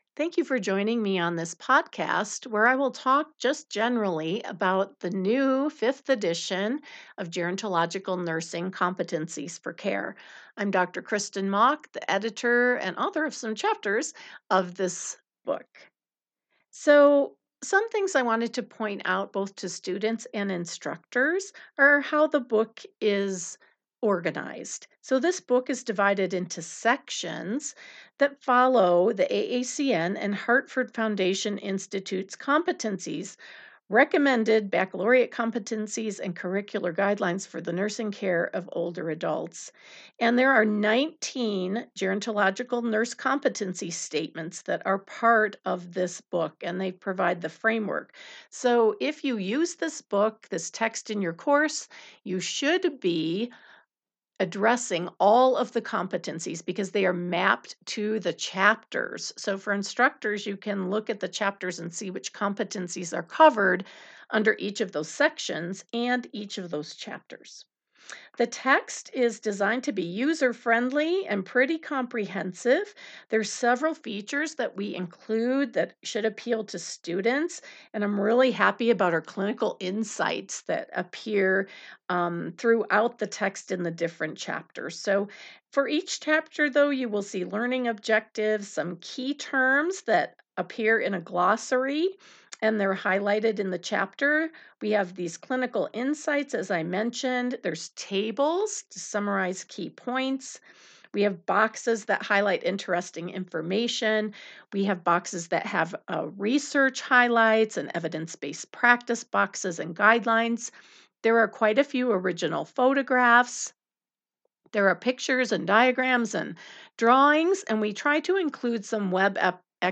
speak directly to instructors about the Fifth Edition's organization, features, pedagogy, and how it addresses the AACN's gerontological nursing competencies.